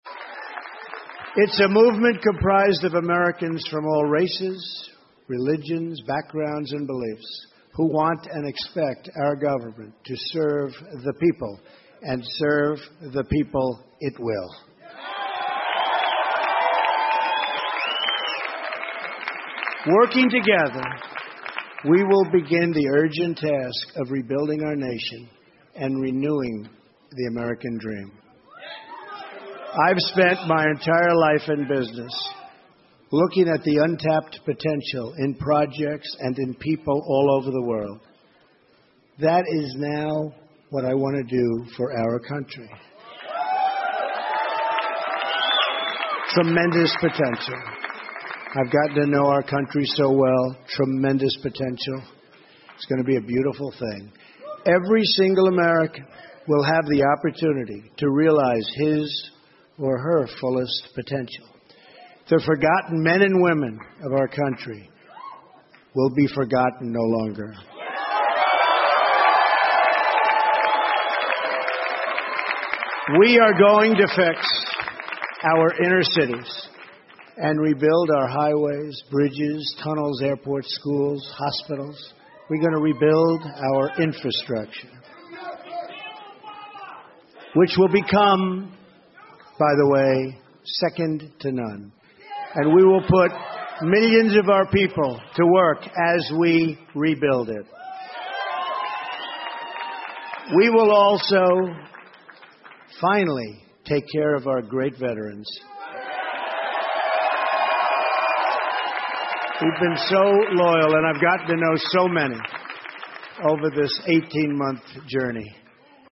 美国总统大选演讲 听力文件下载—在线英语听力室